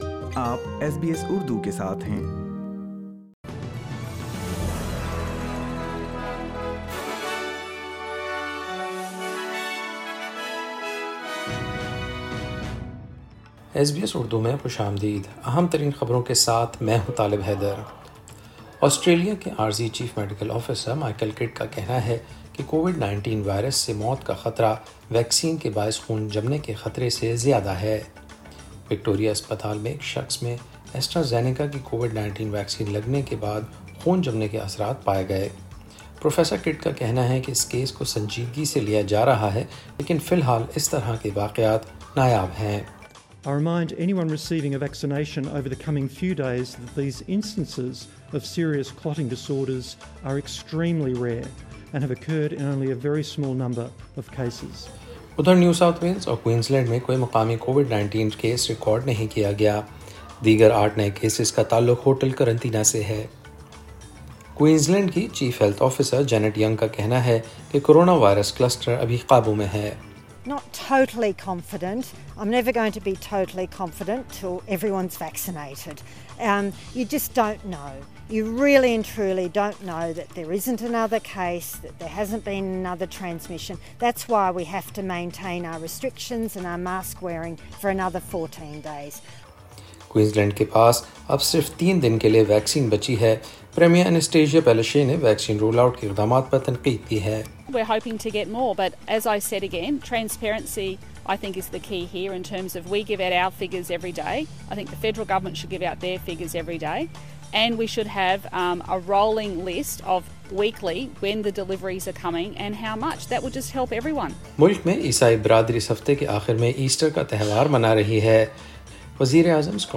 In this bulletin, a man admitted to hospital with blood clots after receiving AstraZeneca coronavirus vaccine. and Queensland Health urges people to remain cautious despite the lifting of restrictions. Catch the latest Urdu news.